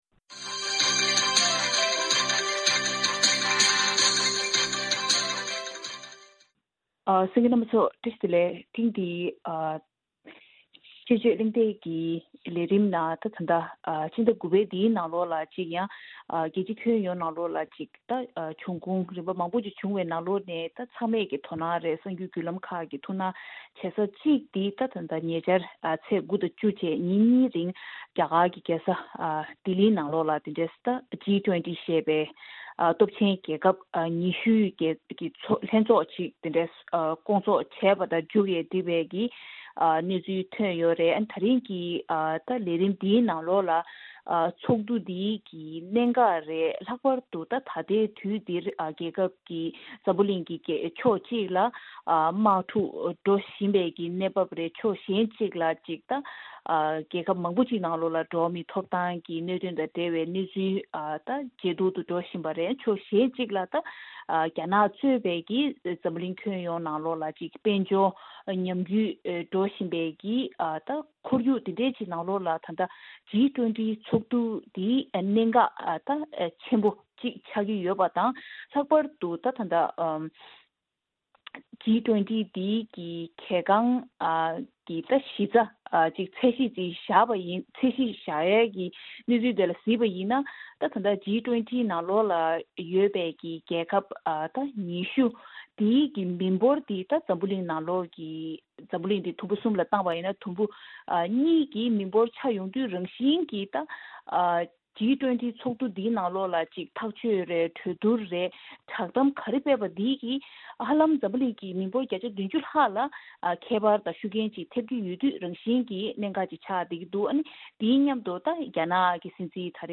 དཔྱད་བརྗོད་གནང་བའི་ལས་རིམ།